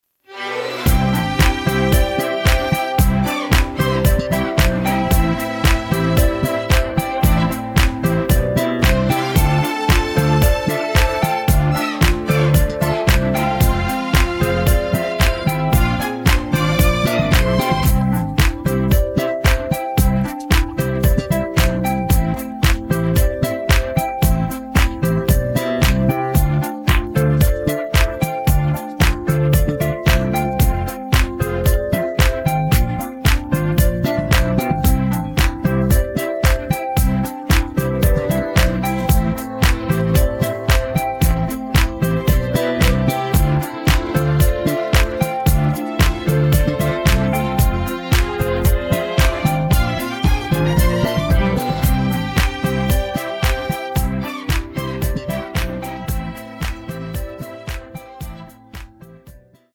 음정 원키 3:50
장르 가요 구분 Voice Cut